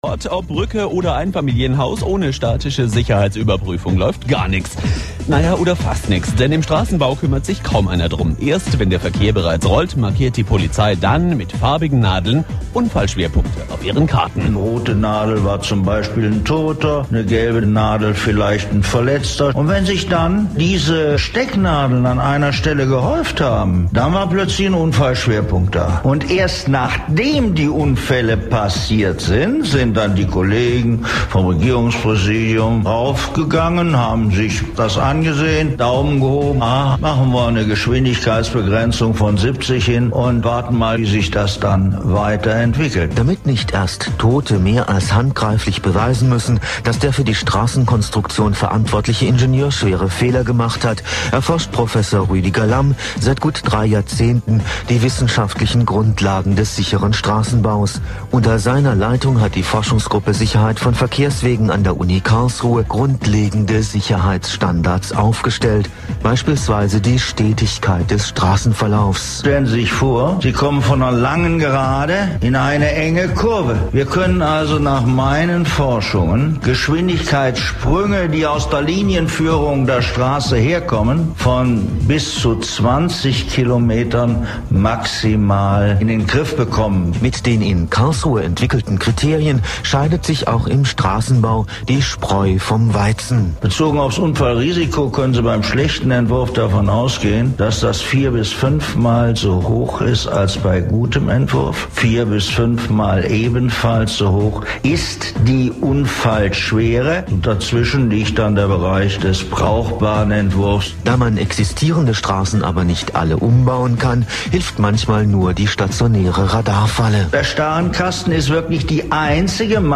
Interviewter